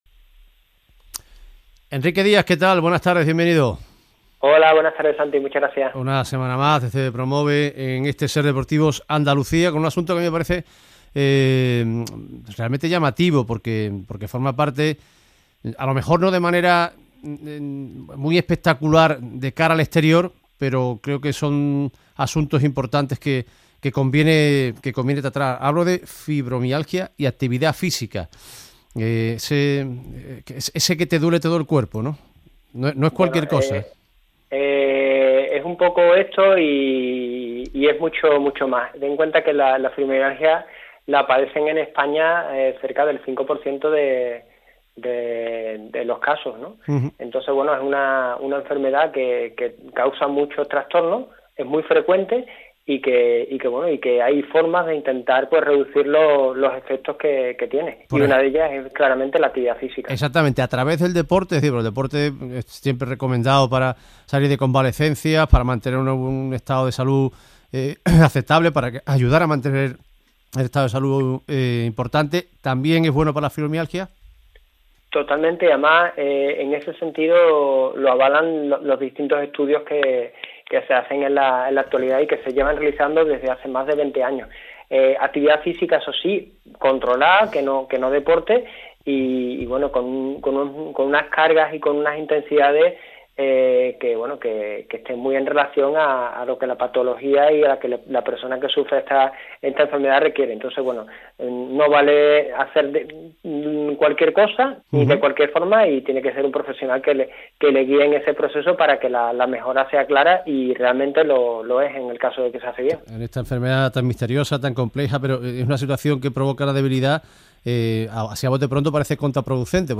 Quizás después de escuchar esta entrevista cambies de opinión y con ayuda de un profesional consigas hacer algo y con ello mejorar tu salud.